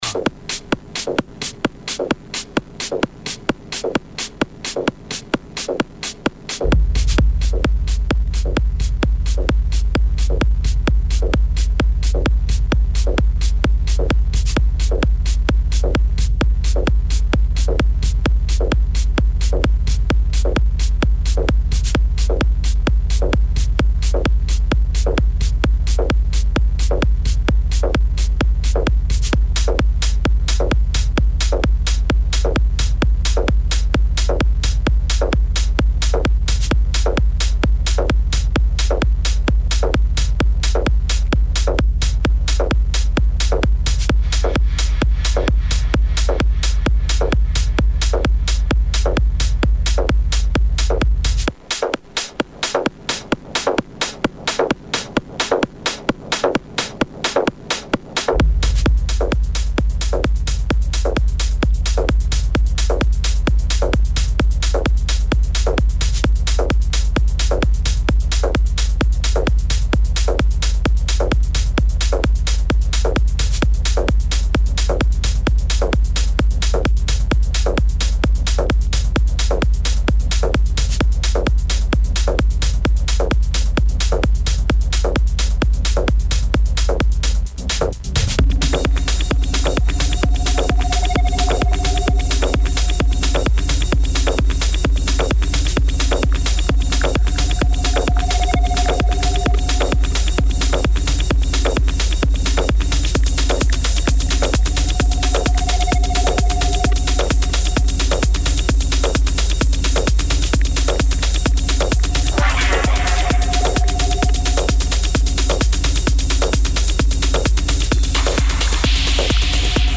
with his fantastic sounds with lot of soul techno and acid!